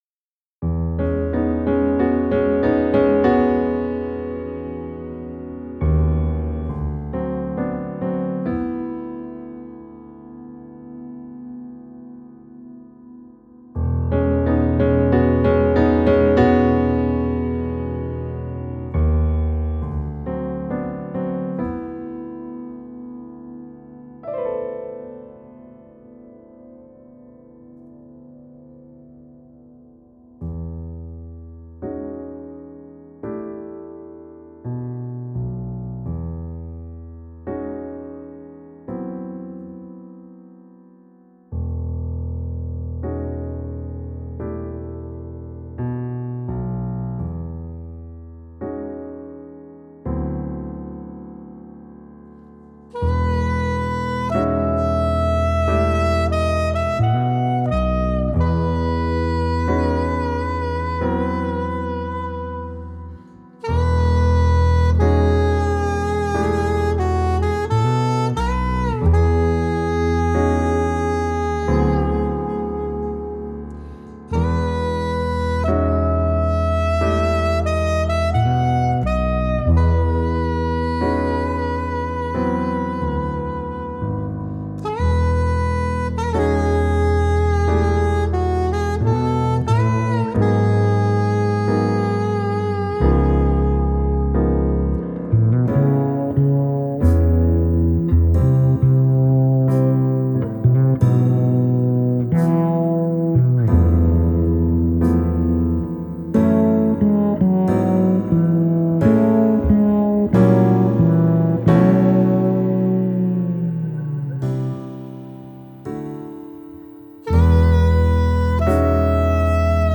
Bestes Lied mit meiner Beteiligung als Tönesortierer/Bassist/Gitarrist ...?